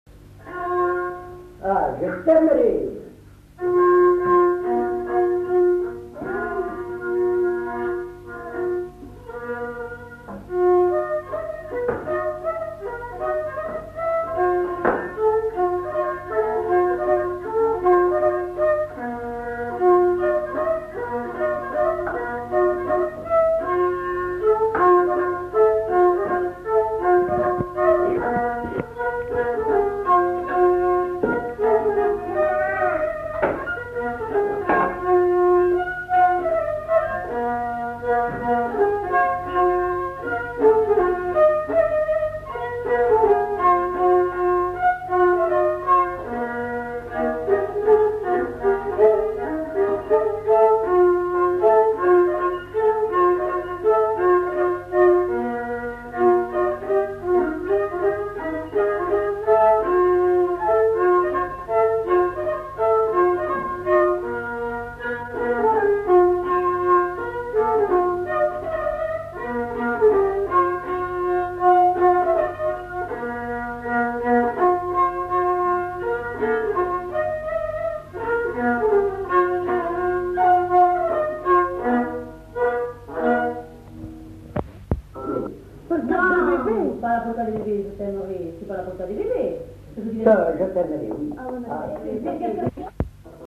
Lieu : Haut-Mauco
Genre : morceau instrumental
Instrument de musique : violon
Danse : varsovienne